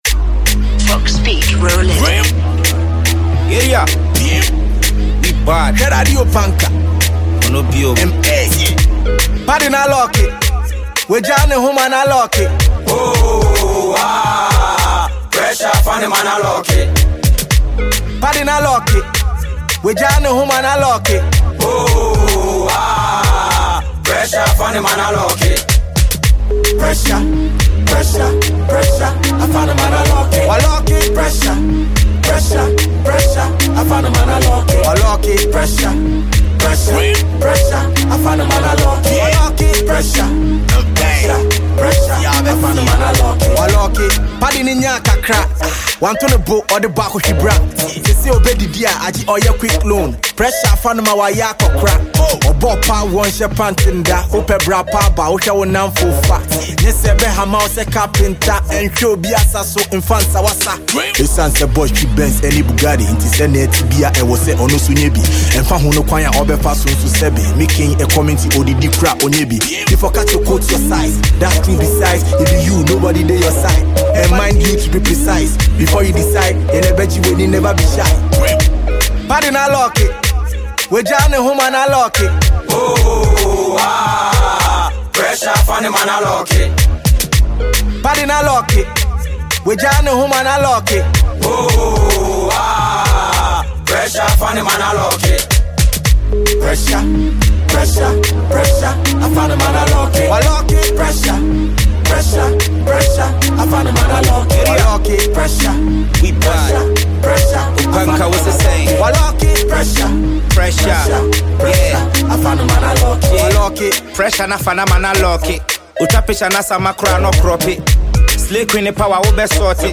Ghanaian rapper
motivational song